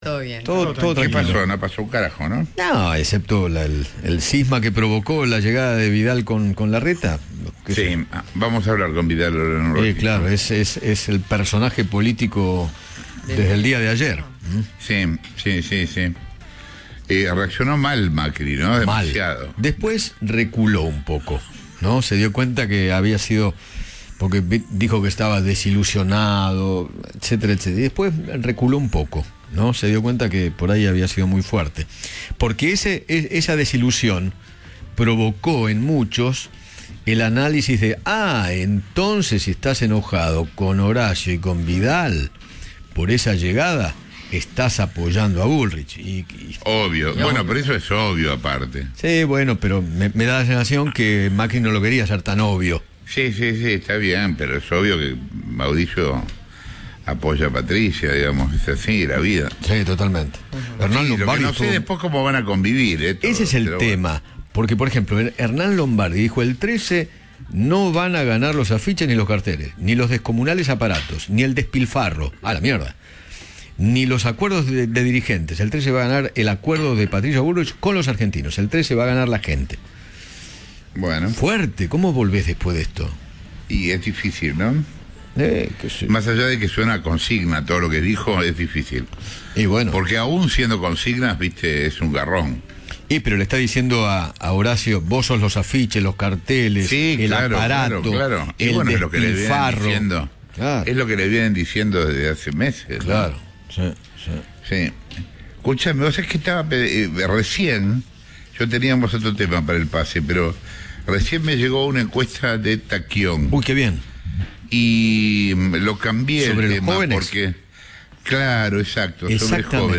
Eduardo Feinmann conversó en el pase con Jorge Lanata sobre el voto de los jóvenes de cara a las PASO 2023.